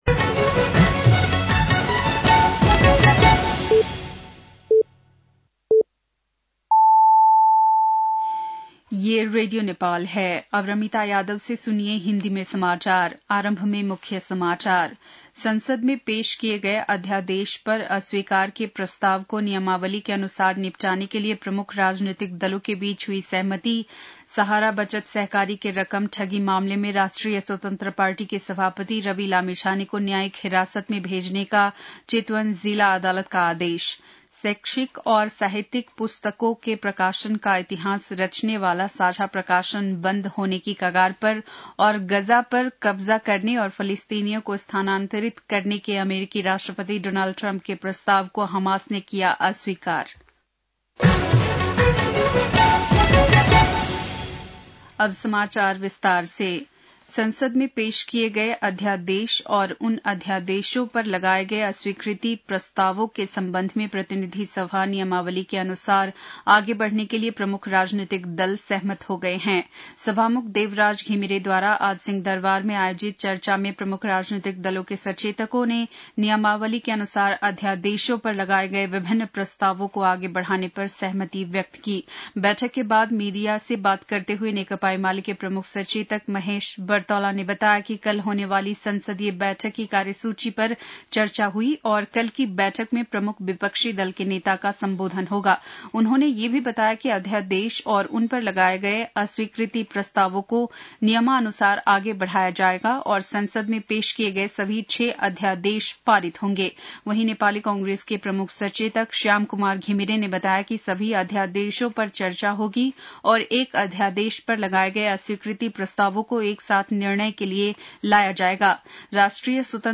बेलुकी १० बजेको हिन्दी समाचार : २४ माघ , २०८१